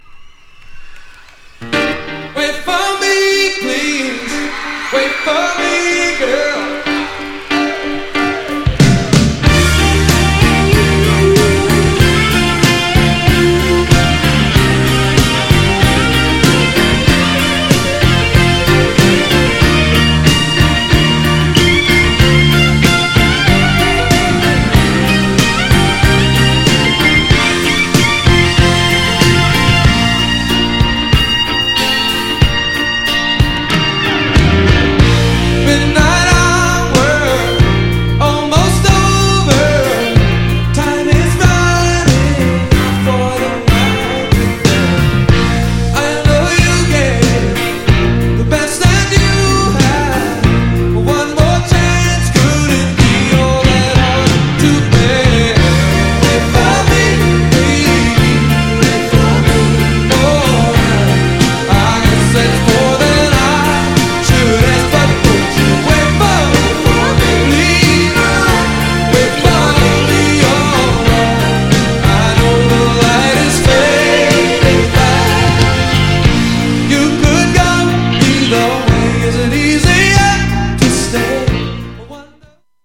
GENRE Dance Classic
BPM 111〜115BPM
エモーショナル # メロウ # ロック # 切ない感じ